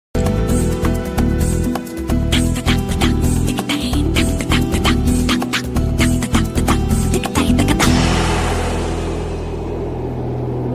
solenoid valve |solenoid valve Working,,,,, sound effects free download